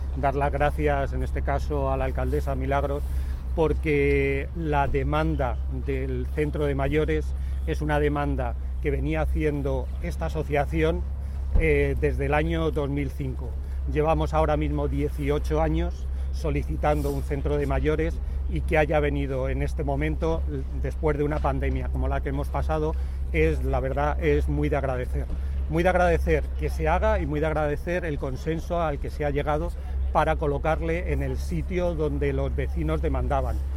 En declaraciones a los medios